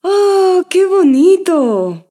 Alabanza de una mujer: ¡Qué bonito!
exclamación
mujer
Sonidos: Voz humana